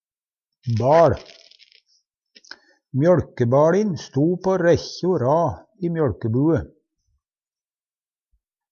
baL - Numedalsmål (en-US)